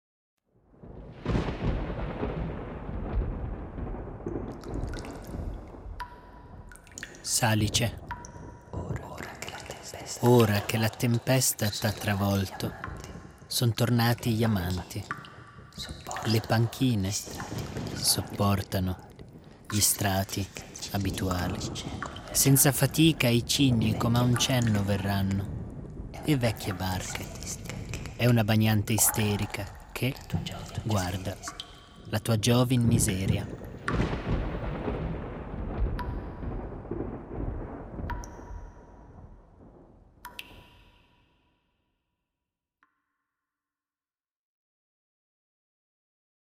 Sonorizzare delle poesie.
Ma proprio l’ermetismo presente permetterebbe una sonorizzazione non didascalica ma creativa, agganciandosi a parametri musicali di quel periodo “di guerra”.